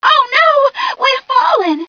mission_voice_t7ca009.wav